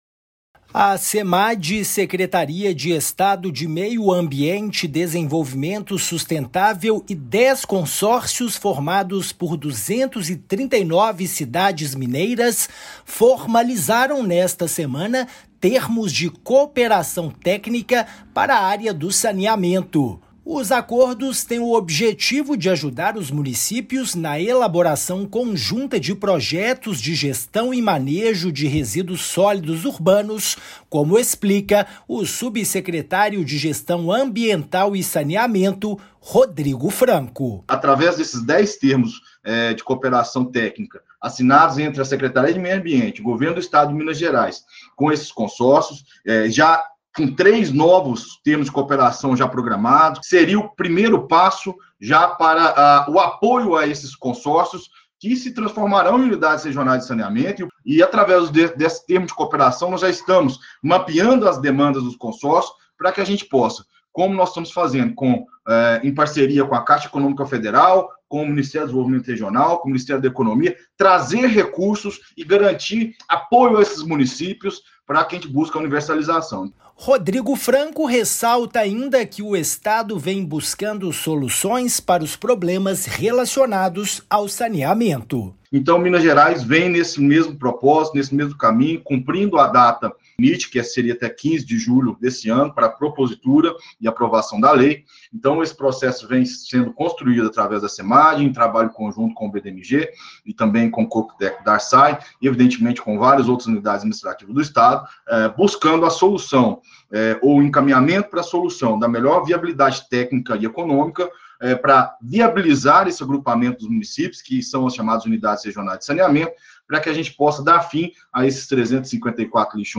Agência Minas Gerais | [RÁDIO] Governo formaliza parceria com municípios para universalizar gestão de resíduos sólidos em Minas
Trabalho de apoio técnico do Estado tem sido fundamental para regionalização e adequação dos serviços. Ouça a matéria de rádio.